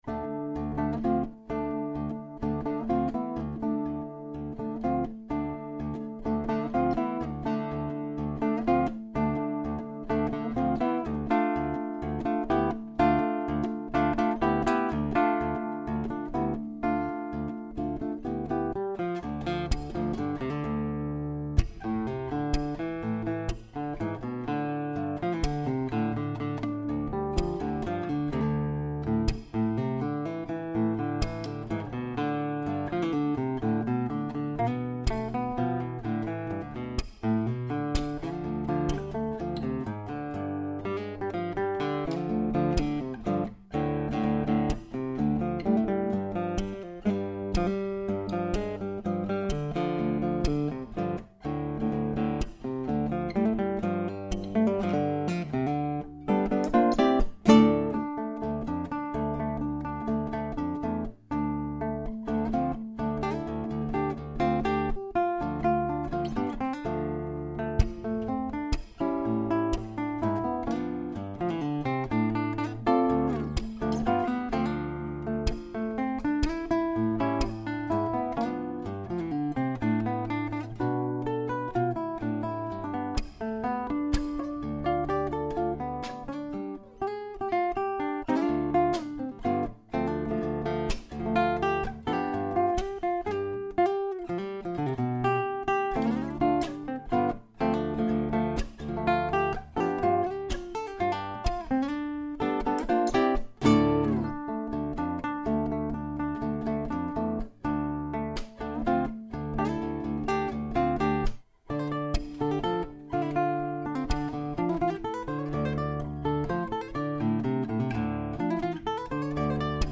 Solo Acoustic Guitarist for Hire
• A toe-tapping mixture of contemporary and classical music
Acoustic Guitarist